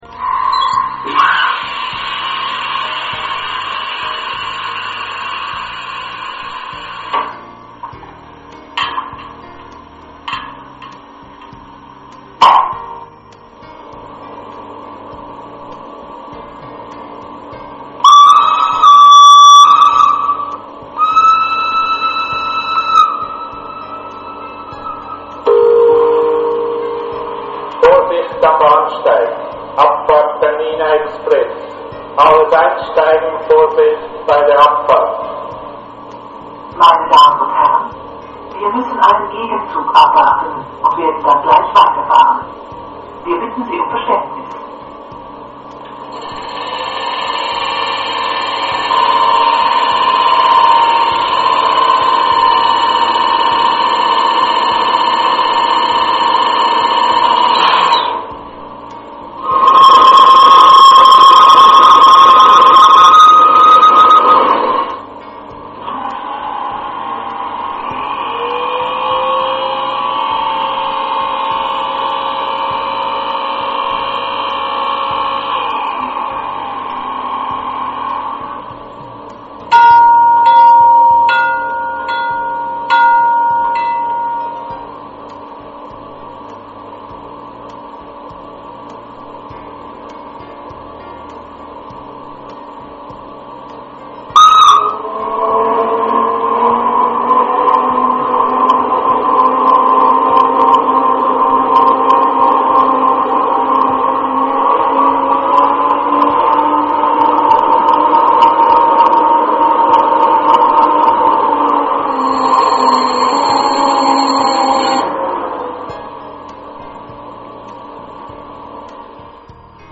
Der eMOTION XLS Sounddecoder gibt die authentischen Geräusche einer Lokomotive in hochwertiger digitaler Qualität wieder. Hierzu werden Soundaufnahmen direkt am Vorbild vorgenommen und dann im Soundlabor für die Elektronik abgeglichen.
Die Hintergrundmusik in den MP3-Demo Dateien ist nicht im XLS-Modul vorhanden!
Soundgeräusch